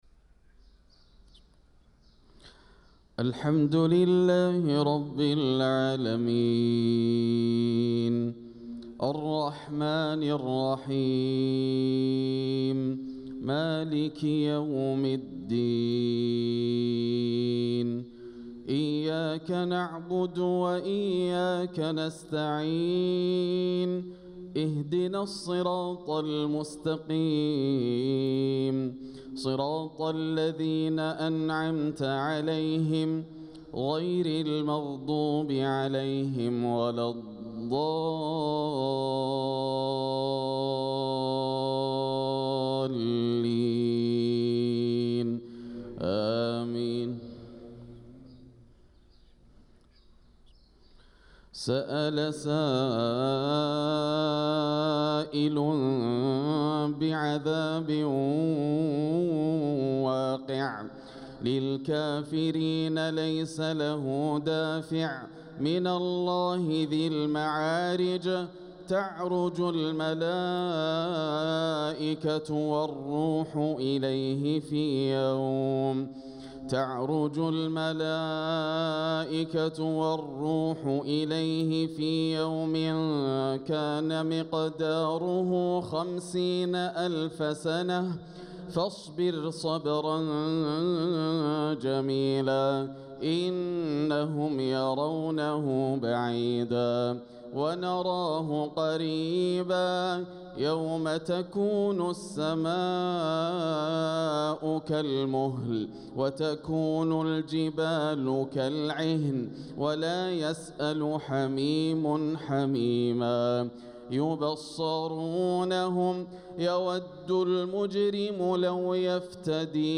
صلاة الفجر للقارئ ياسر الدوسري 19 صفر 1446 هـ
تِلَاوَات الْحَرَمَيْن .